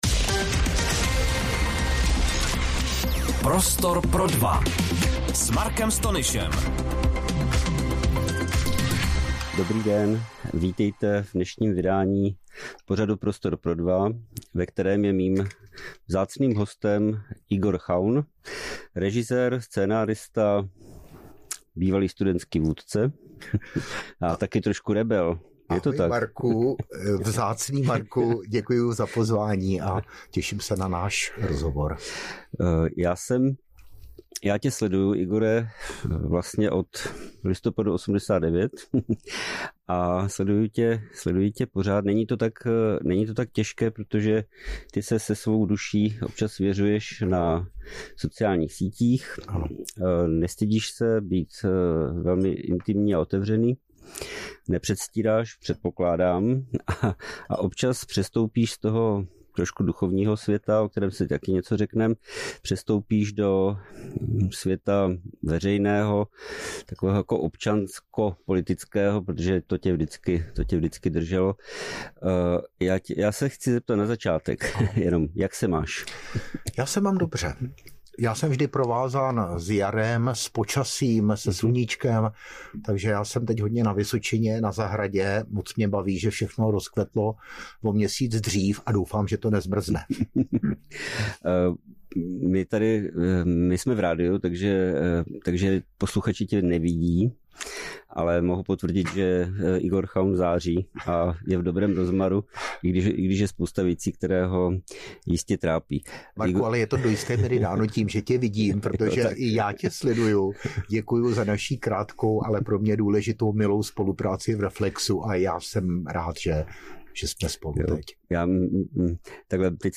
se v rozhovoru dělí o své hluboké pohledy na svět, který prochází turbulencemi války na Ukrajině, pandemií covidu-19 a politickou polarizací.